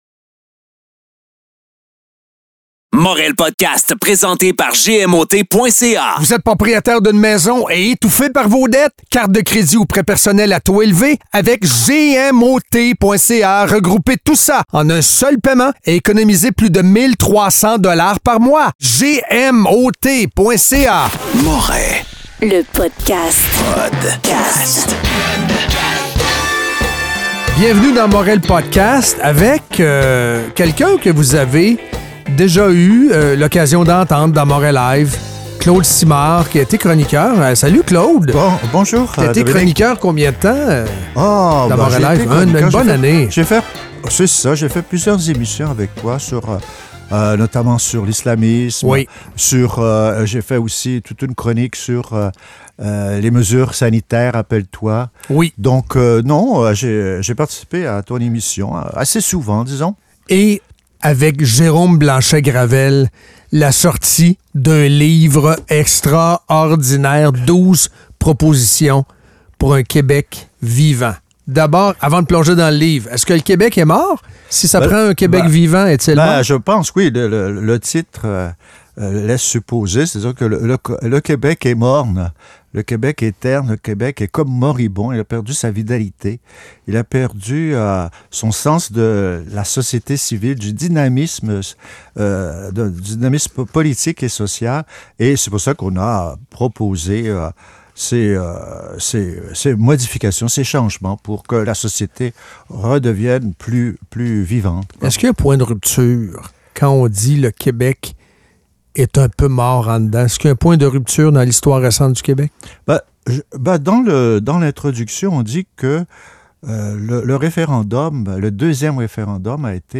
Un entretien coup-de-poing.